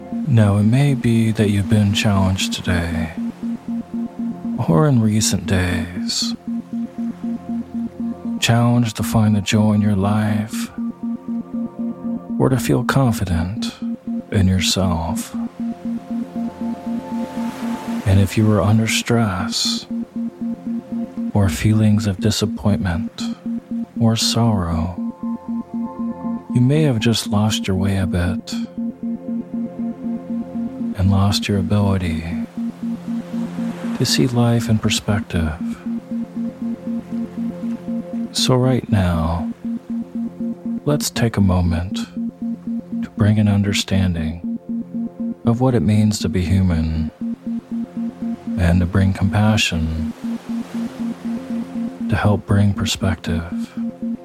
Meditation for Self-Love With Shamanic Drumming (Sleep Hypnosis) With Isochronic Tones
The music was composed around the note “F” which some relate to the heart chakra.
Self-Love-Shamanic-Drumming-ISO-Sample.mp3